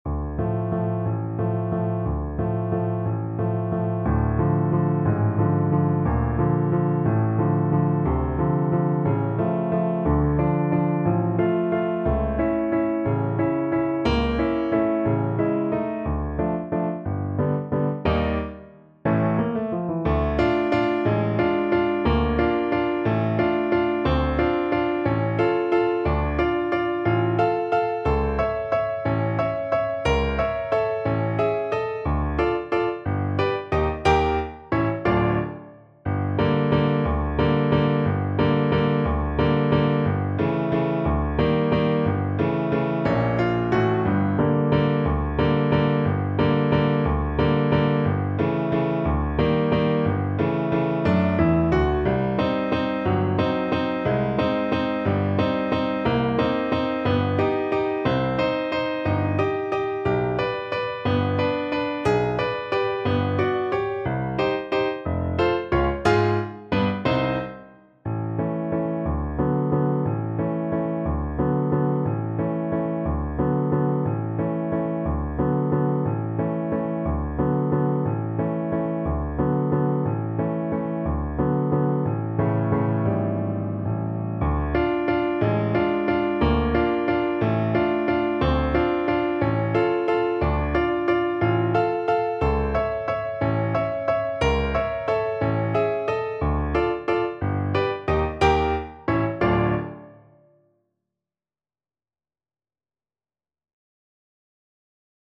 3/4 (View more 3/4 Music)
Allegro espressivo .=60 (View more music marked Allegro)
Classical (View more Classical French Horn Music)